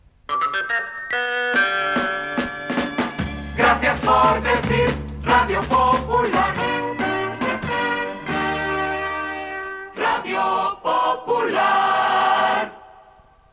Indicatiu cantat